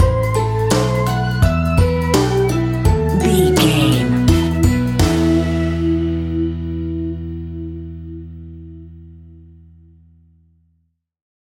Aeolian/Minor
instrumentals
fun
childlike
happy
kids piano